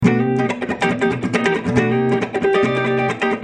Free MP3 funk music guitars loops & sounds 3
guitar loop - funk 42